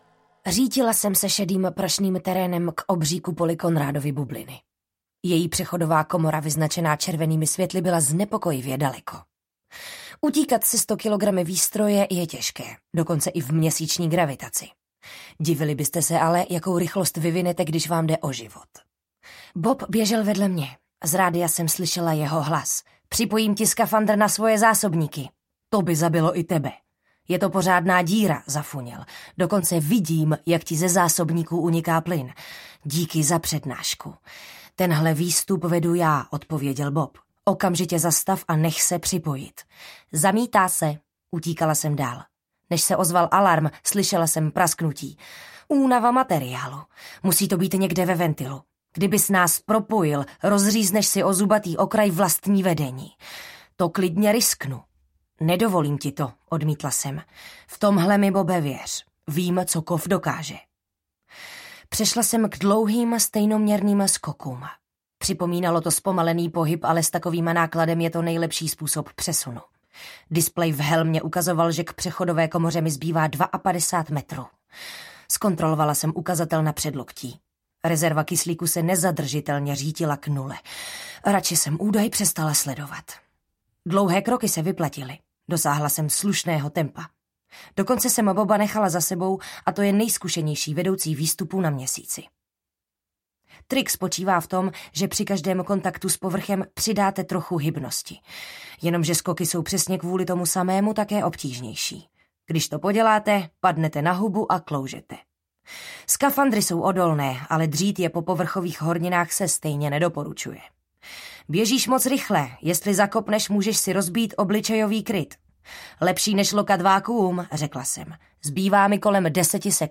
Artemis audiokniha
Ukázka z knihy